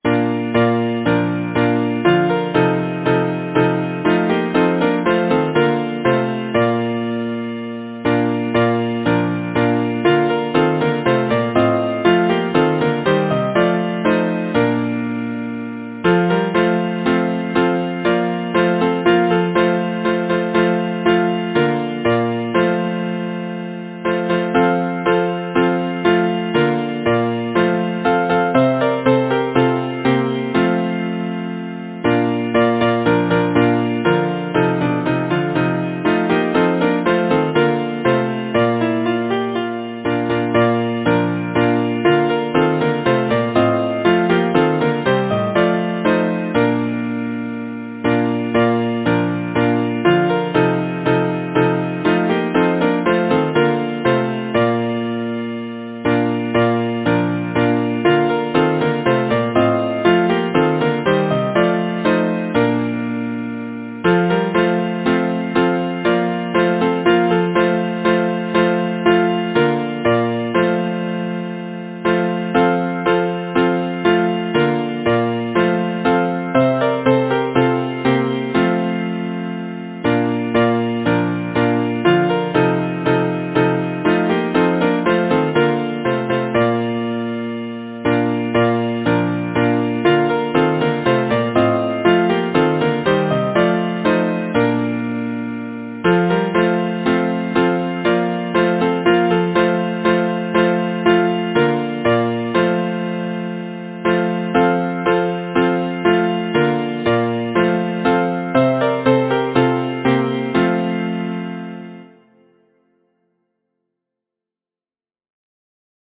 Title: The Watermill Composer: George W. Fields Lyricist: A. K. Millscreate page Number of voices: 4vv Voicing: SATB Genre: Secular, Partsong
Language: English Instruments: A cappella